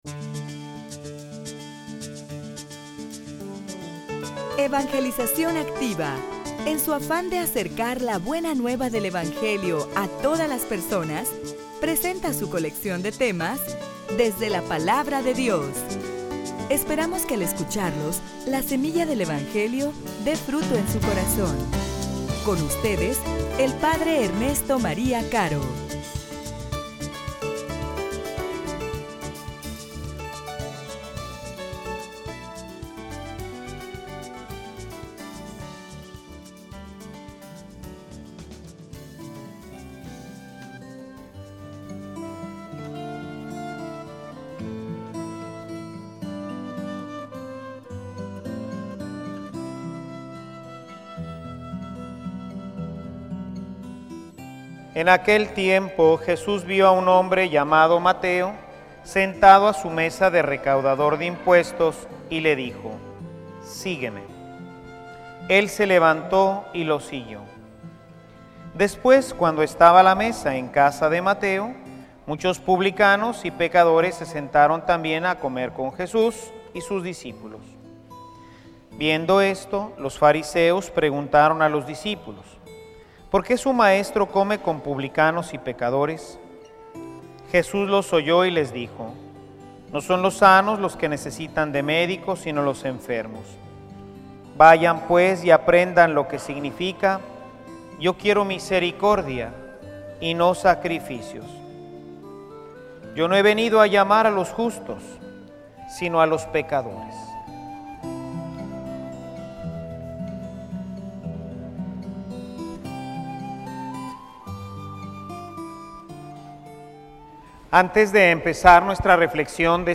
homilia_Los_nuevos_fariseos.mp3